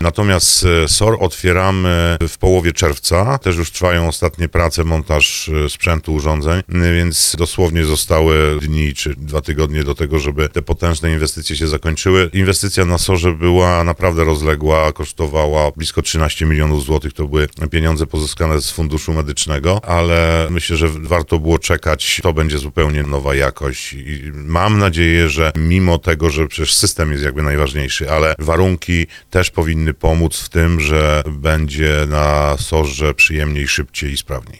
– mówi starosta brzeski Andrzej Potępa